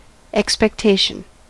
Ääntäminen
IPA : /ɛkspɛkˈteɪʃən/